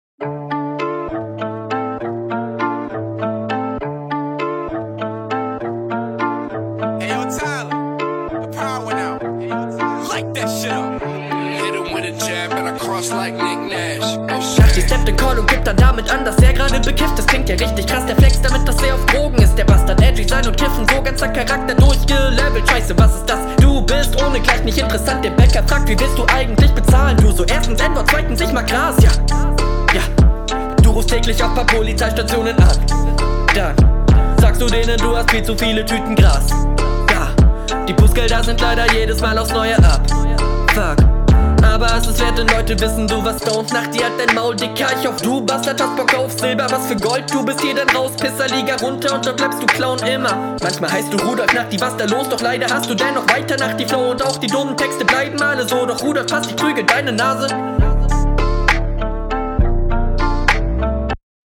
Sehr starker Flow von 0:30 bis 0:42